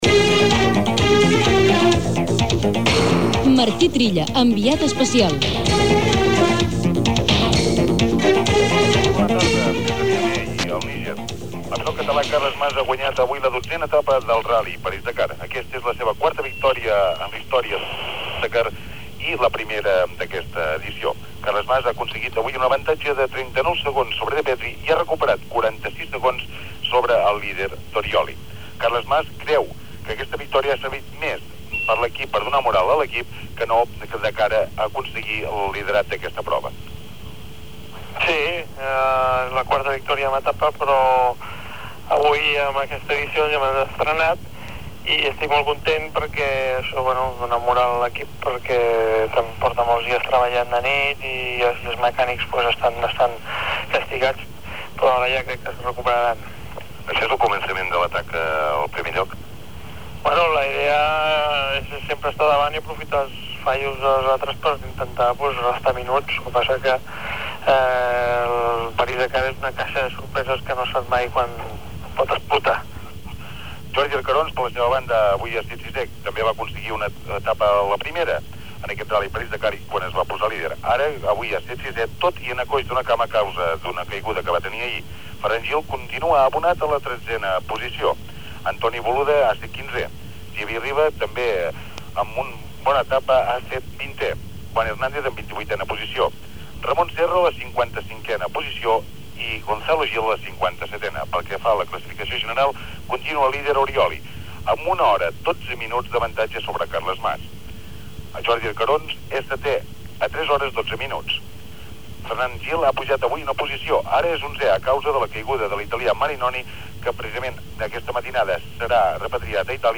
Crònica telefònica de la jornada del ral·li París-Dakar, des del Níger. Al final del fragment s'escolta una falca de publicitat de Caixa de Manresa, patrocinador de l'equip Caixa de Manresa.
Esportiu